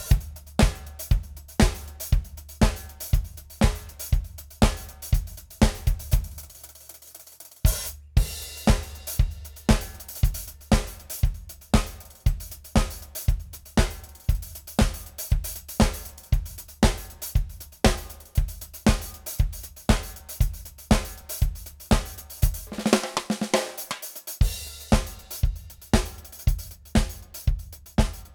Since I can send it via midi, but it's one velocity, and it doesn't catch rim hits or quieter snare hits.
That fill at the end was a son of a bitch to match up right...
The new clip sound OK to me.